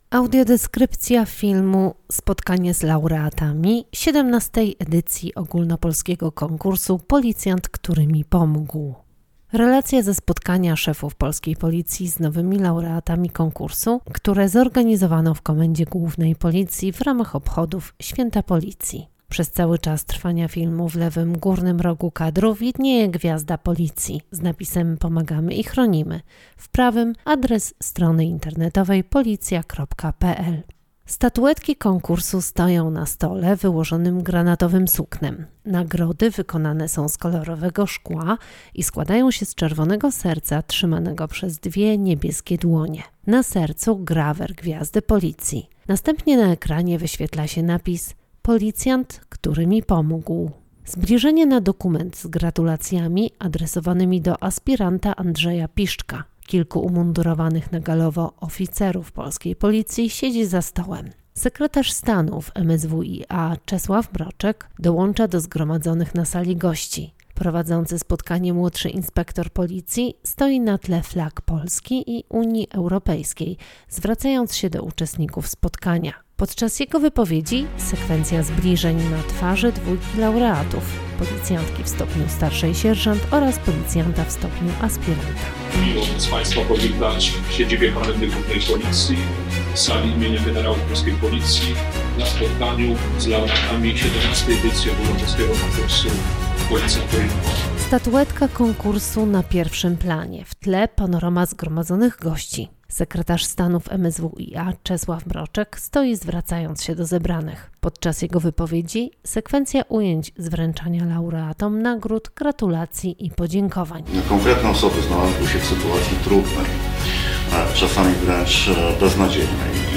Nagranie audio Audiodeskrypcja do filmu: Spotkanie z laureatami XVII edycji Ogólnopolskiego Konkursu - Policjant, który mi pomógł